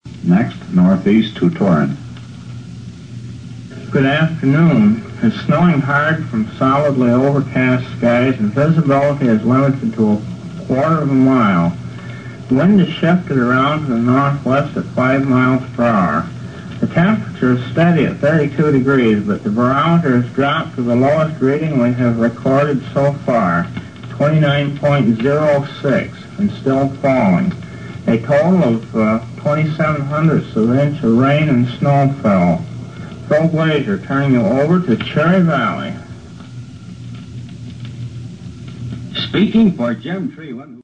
Aircheck of non-copyrighted broadcast material (weather observations and National Weather Service forecast)